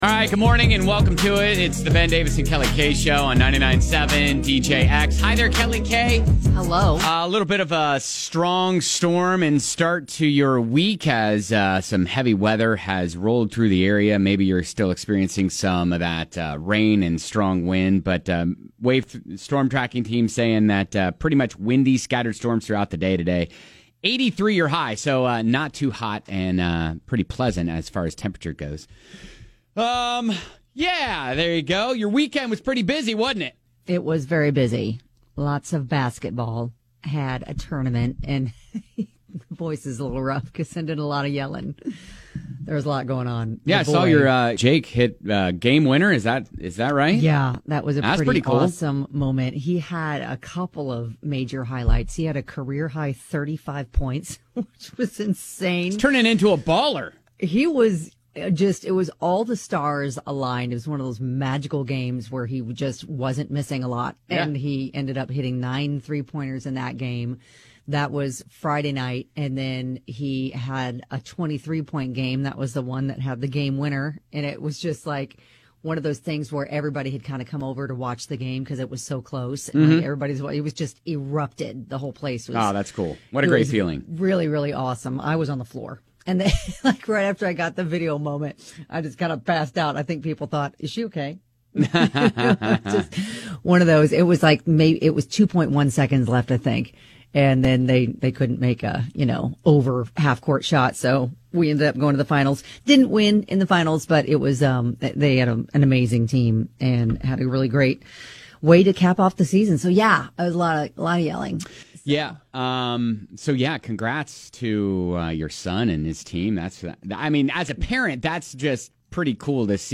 Awkward sounds thanks to Guinness World Records...